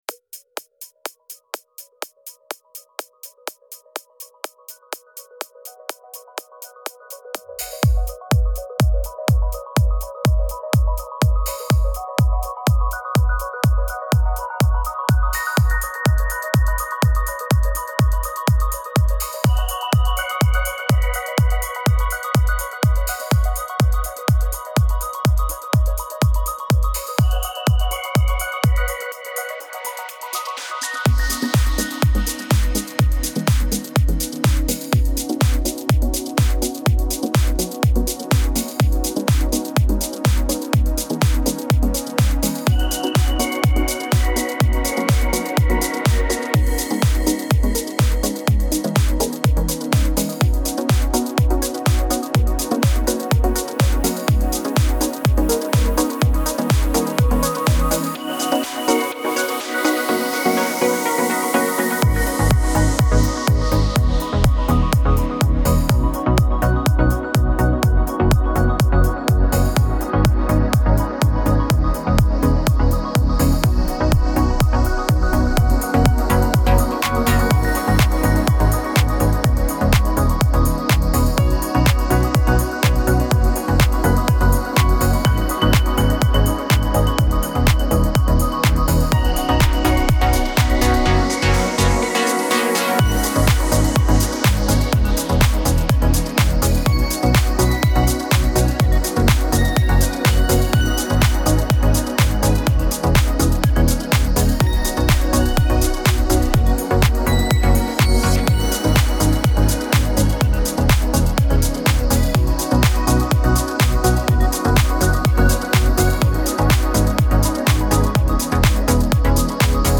امید‌بخش , پر‌انرژی , رقص , موسیقی بی کلام